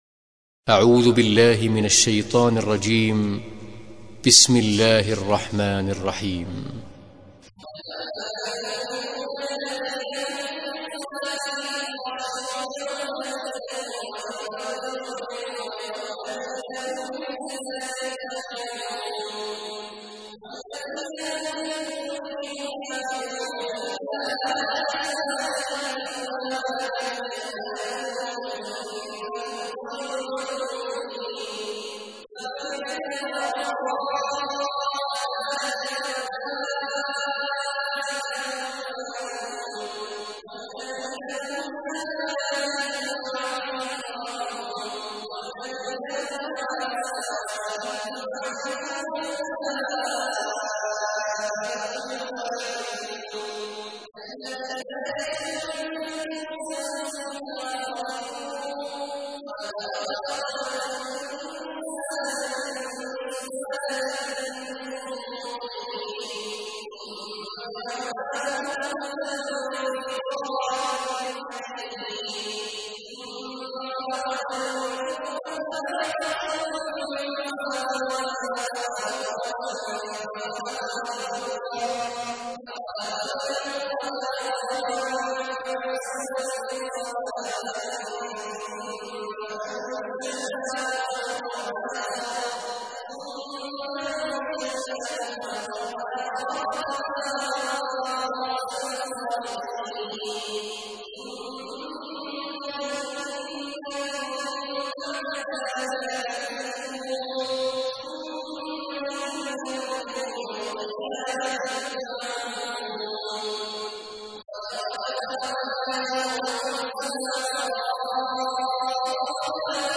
تحميل : 23. سورة المؤمنون / القارئ عبد الله عواد الجهني / القرآن الكريم / موقع يا حسين